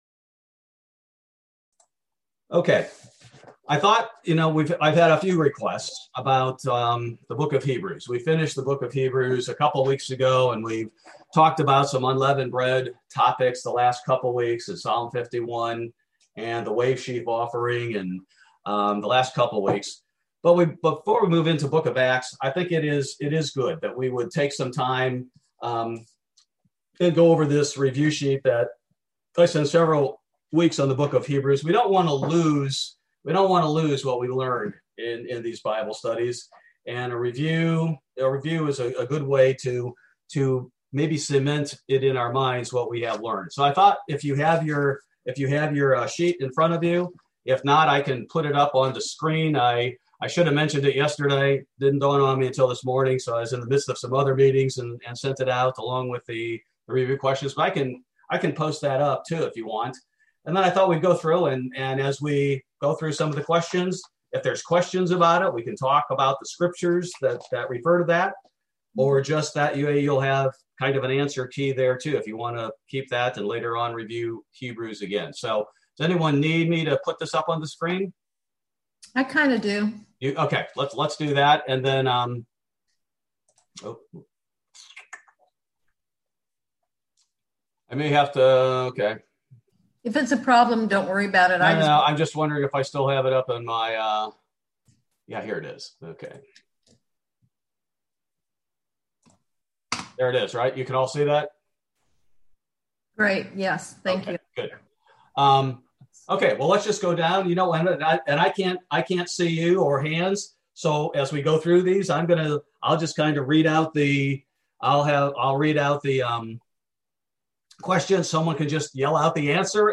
Bible Study: April 7, 2021